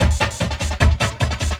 45 LOOP 11-R.wav